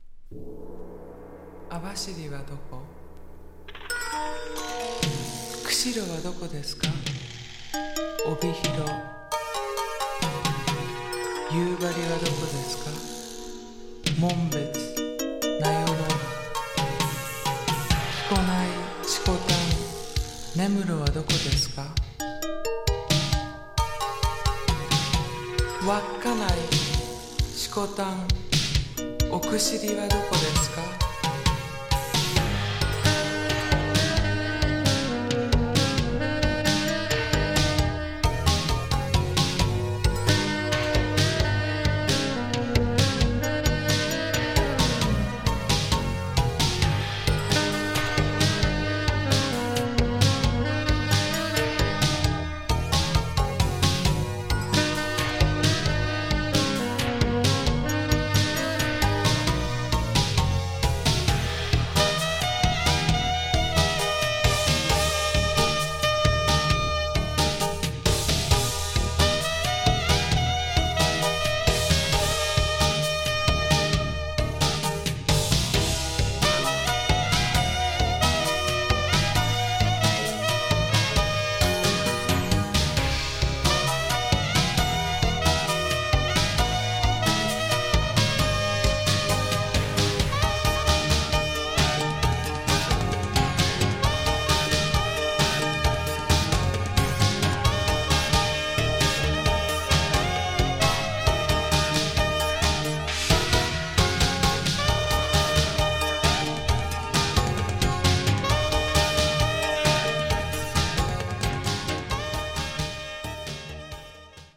スーパーマーケットでかかっていそうなエモい80'sフュージョンが好きな方にオススメの一枚！
【JAZZ FUNK】【FUSION】